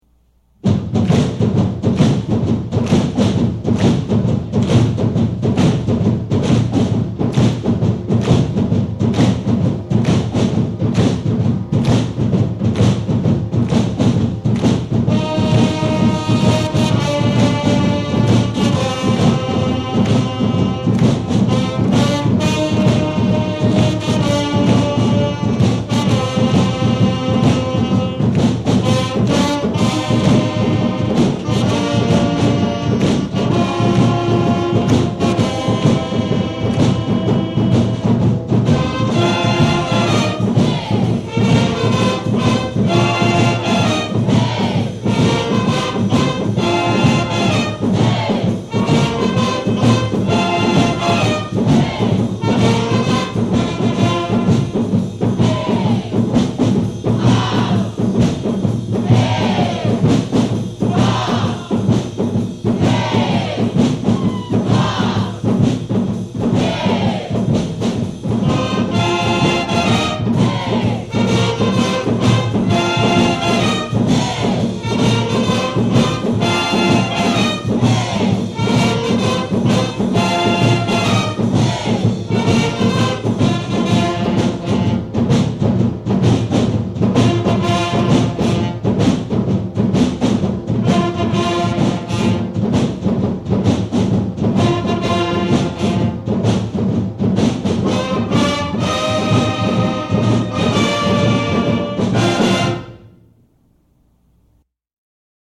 Click on the titles below to hear the Greatest Pep Band of All-Time play your favorite hits!